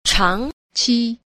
5. 長期 – chángqí – trường kỳ
chang_qi.mp3